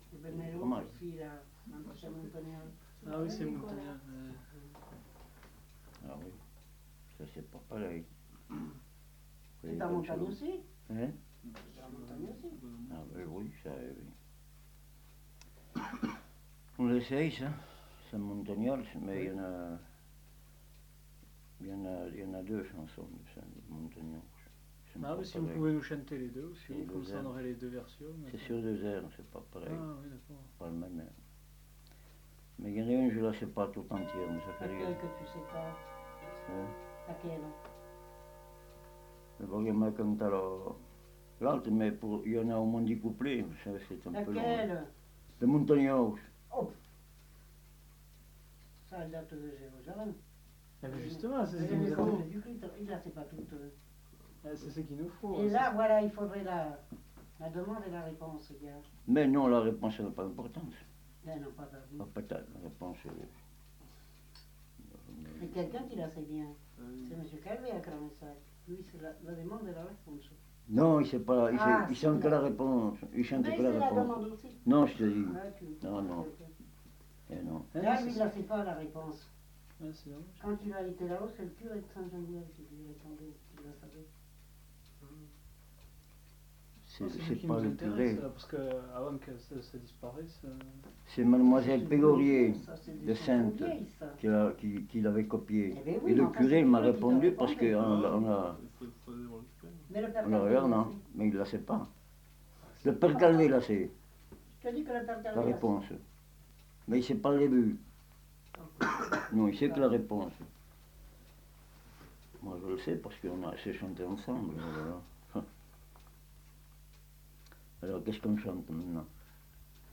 Lieu : Vernholles (lieu-dit)
Genre : chant
Effectif : 1
Type de voix : voix d'homme
Production du son : chanté
Classification : chanson identitaire